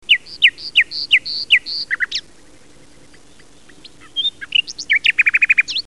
Erano trilli tenui, delicati, vellutati, pieni più di dolcezza che di forza.
usignolo.mp3